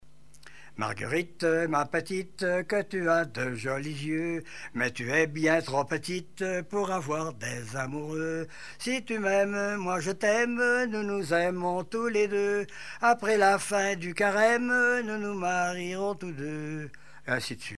Chants brefs - A danser
danse : mazurka
Chansons et témoignages
Pièce musicale inédite